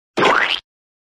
Squashing Sound Button - Free Download & Play
Sound Effects Soundboard70 views